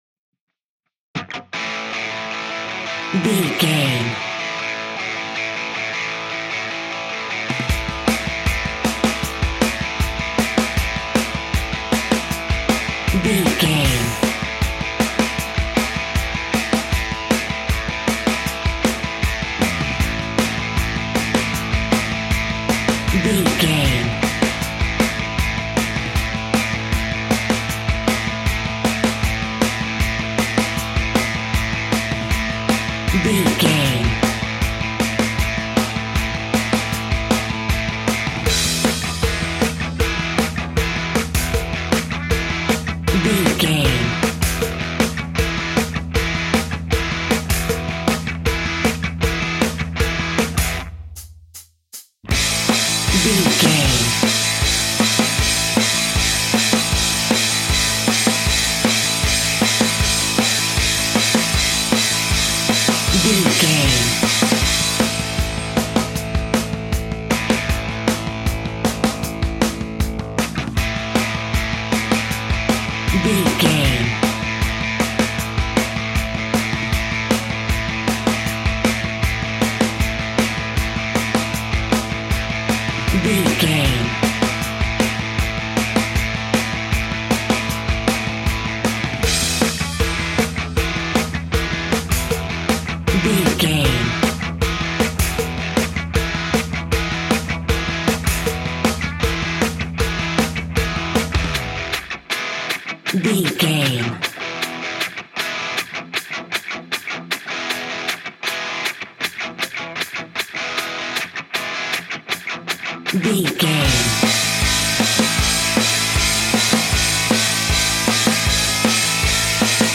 Ionian/Major
Fast
energetic
driving
aggressive
electric guitar
bass guitar
drums
hard rock
heavy metal
blues rock
distortion
instrumentals
heavy drums
distorted guitars
hammond organ